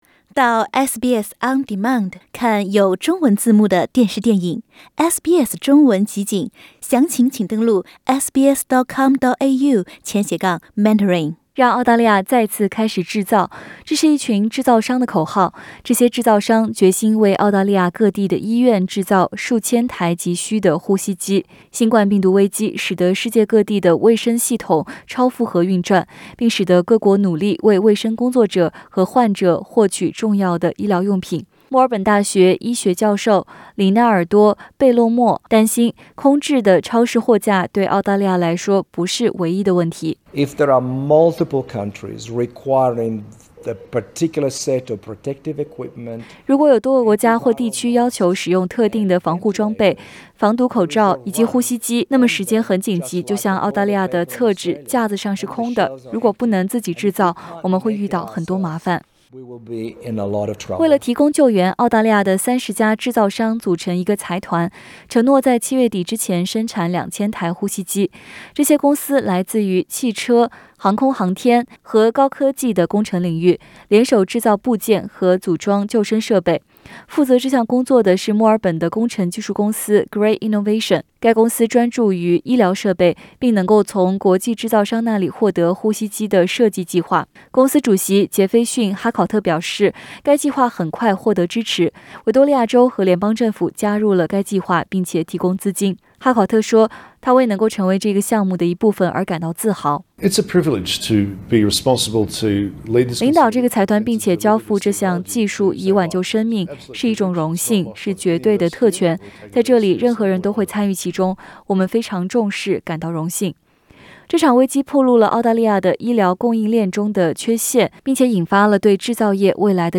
Source: SBS SBS 普通话电台 View Podcast Series Follow and Subscribe Apple Podcasts YouTube Spotify Download (7.19MB) Download the SBS Audio app Available on iOS and Android 新冠病毒危机暴露了澳大利亚医疗供应链中的短缺，并引发了对澳大利亚制造业未来的讨论。